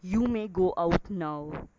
Sentance " DPSA G17 ANGRY TONE - 声音 - 淘声网 - 免费音效素材资源|视频游戏配乐下载
“请以愤怒的语气出去”